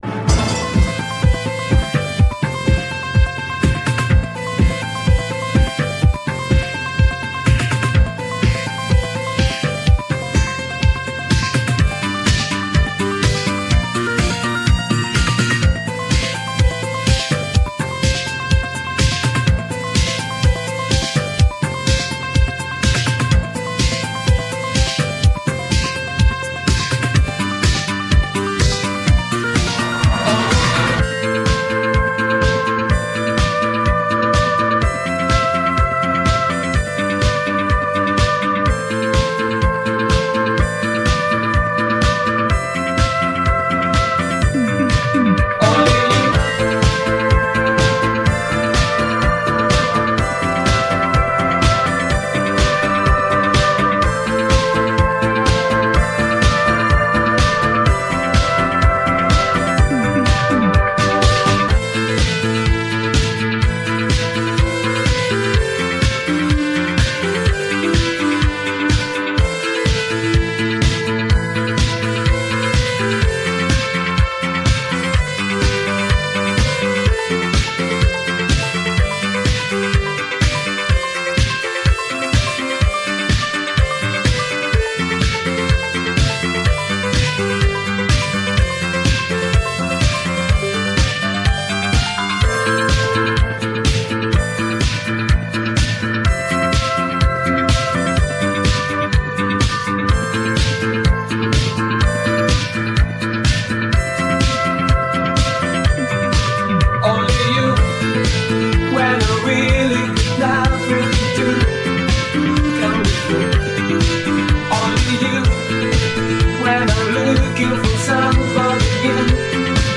A mashup I created with the Italo Disco song